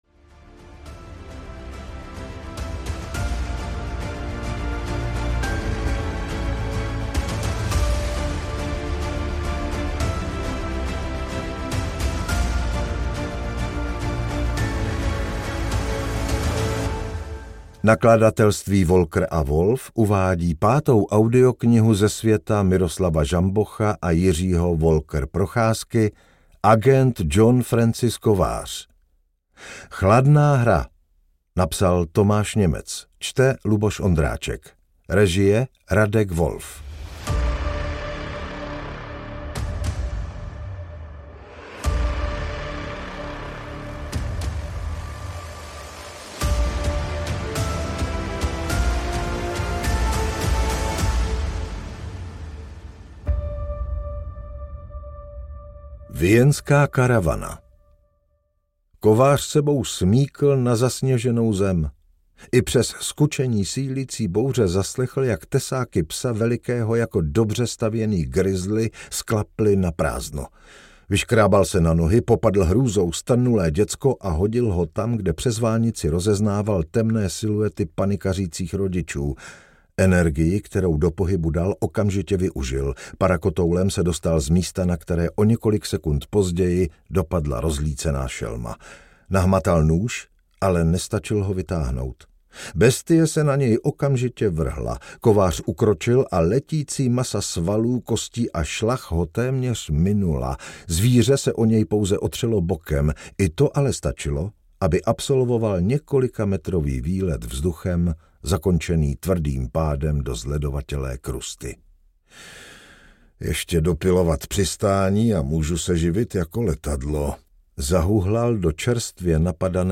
Chladná hra audiokniha
Ukázka z knihy
chladna-hra-audiokniha